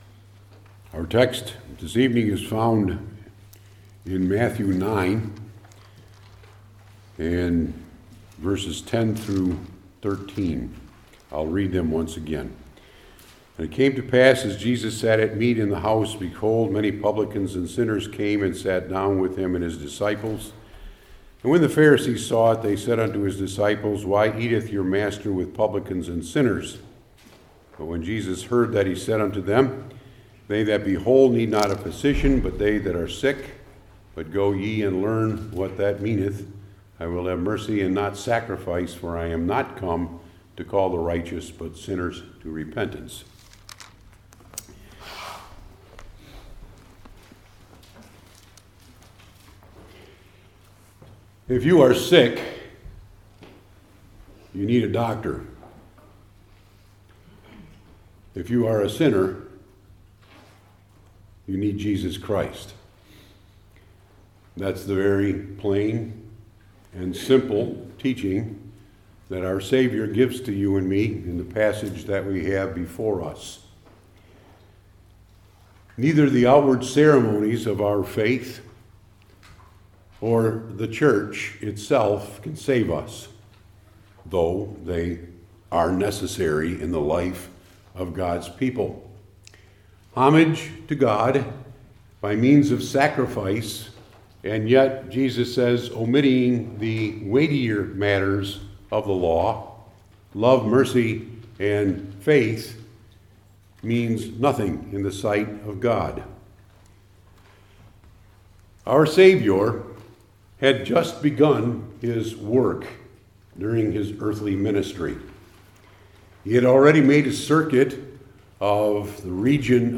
New Testament Individual Sermons I. The Occasion II.